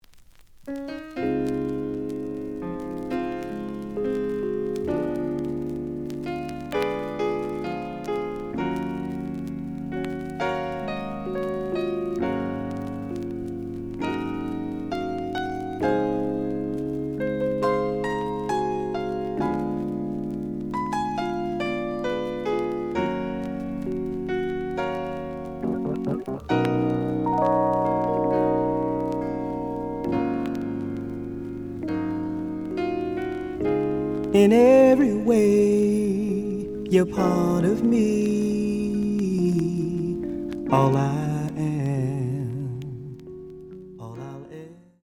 試聴は実際のレコードから録音しています。
●Genre: Disco
●Record Grading: VG+~EX- (盤に若干の歪み。多少の傷はあるが、おおむね良好。)